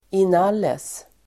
Ladda ner uttalet
Folkets service: inalles inalles adverb, in all Uttal: [in'al:es] Definition: tillsammans, allt som allt Exempel: publiken bestod av inalles nio personer (the audience consisted of nine persons in all)